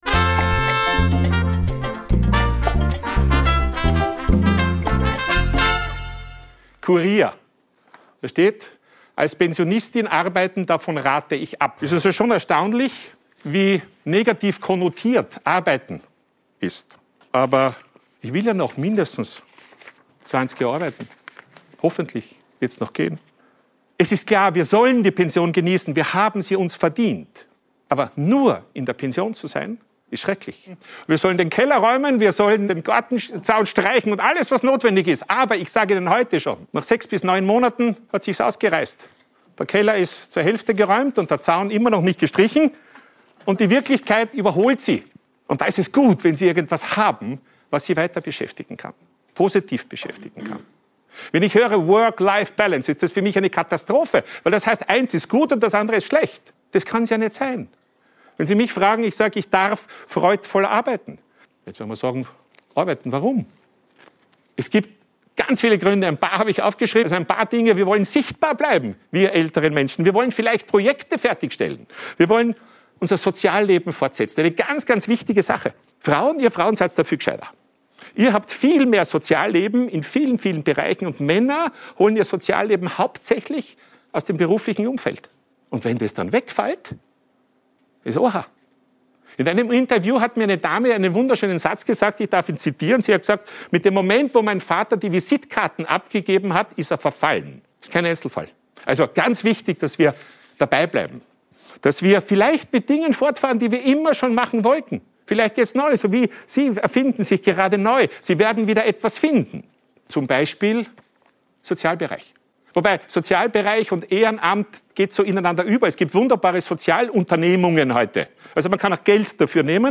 Heute gibt es einen Ausschnitt aus einem Vortrag bei der
Wirtschaftskammer Österreich, gehalten letztes Jahr.